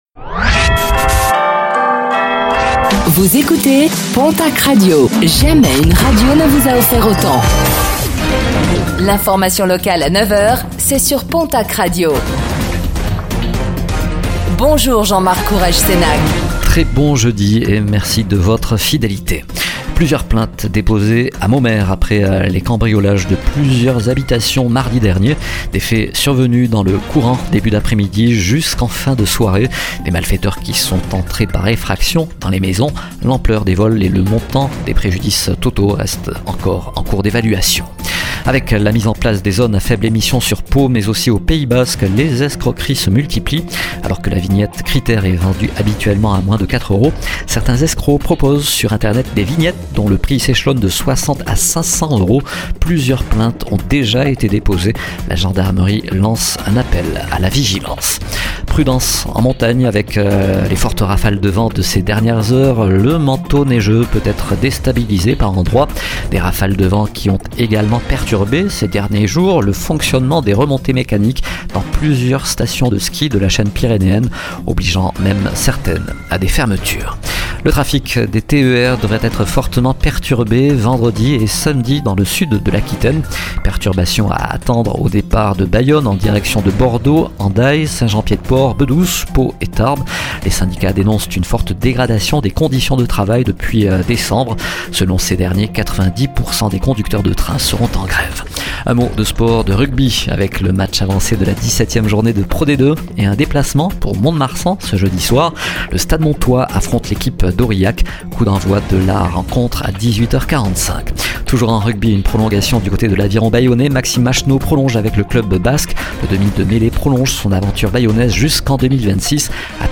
Réécoutez le flash d'information locale de ce jeudi 30 janvier 2025